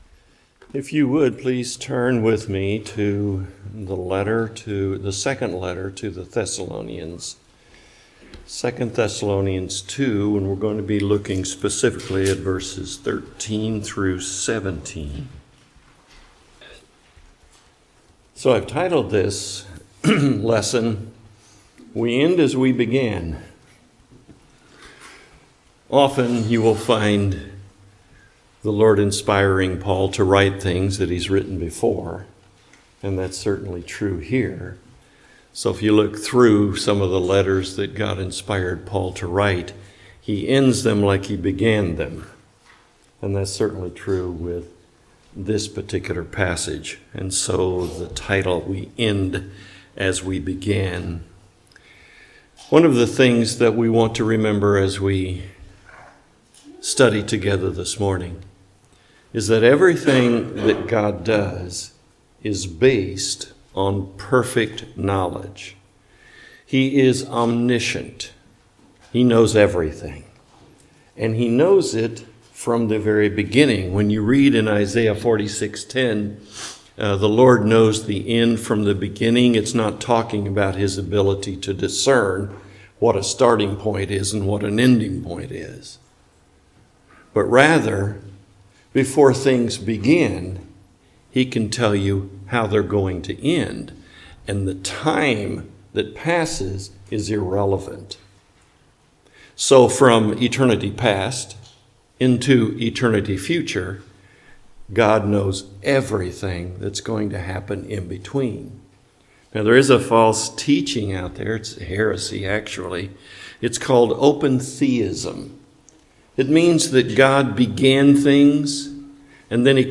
2 Thessalonians Passage: 2 Thessalonians 2:13-17 Service Type: Morning Worship « Looking Down the Road